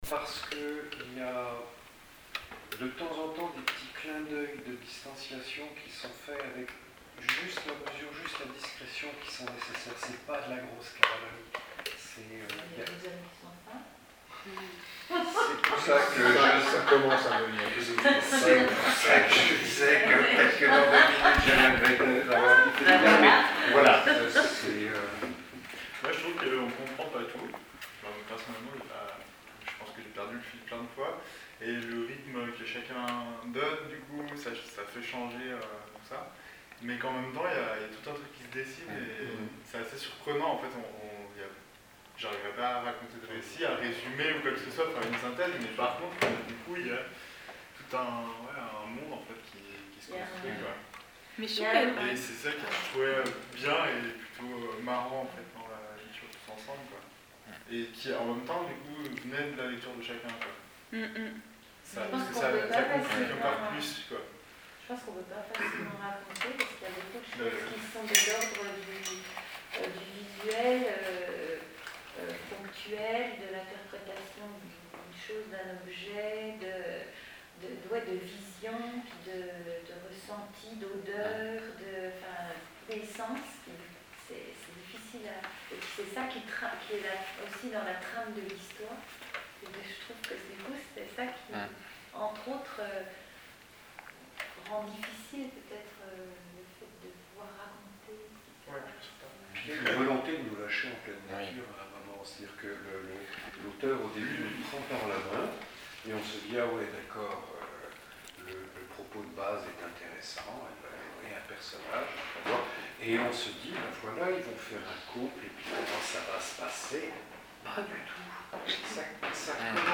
Lieu : Crac Alsace, Altkirch
La bibliothèque en vadrouille a saisi deux instants, le premier pendant la lecture et le second quelques minutes après la fin de la lecture collective, où des réactions spontanées ont émergées.
• Tour de table collectif quelques minutes après la lecture :